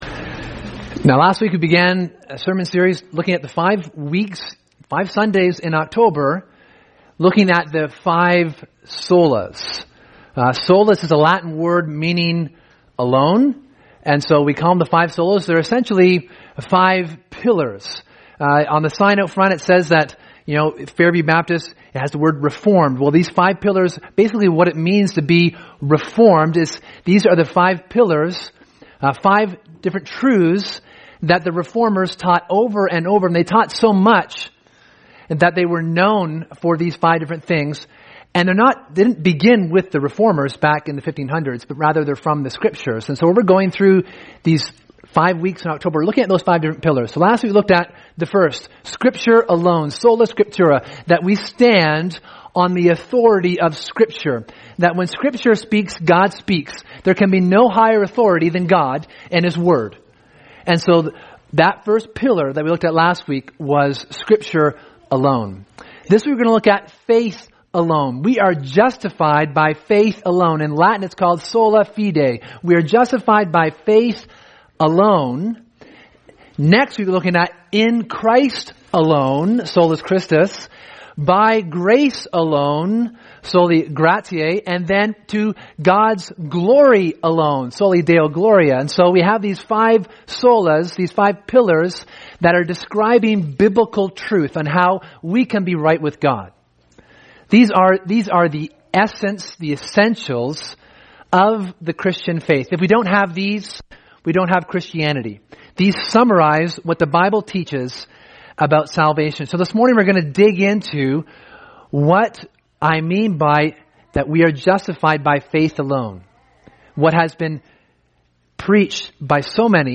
Sermon: Faith Alone